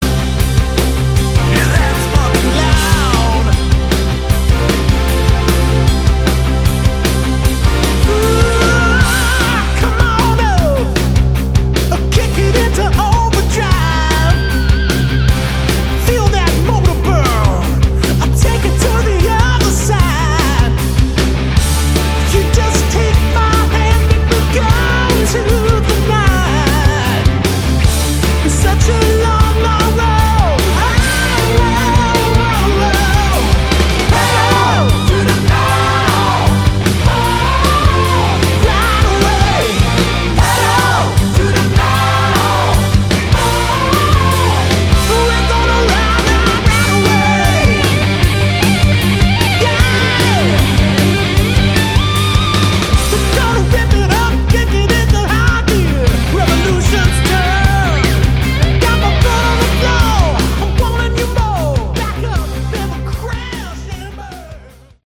Category: AOR/ Melodic Rock